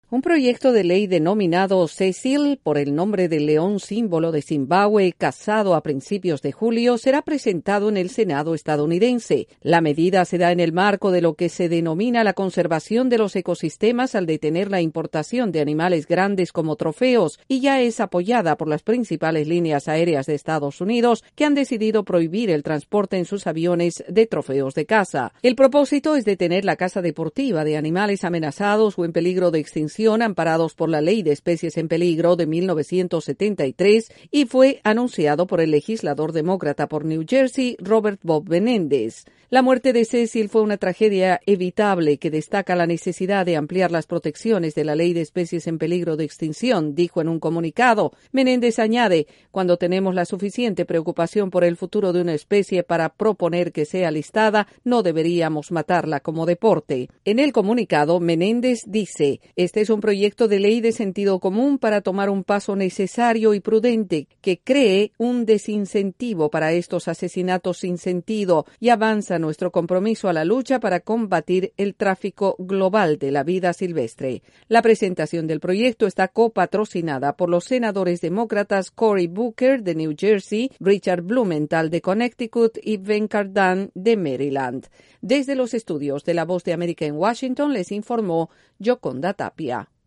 Un grupo de senadores propone una ley para proteger a los animales en peligro de extinción de los cazadores. Desde la Voz de América en Washington informe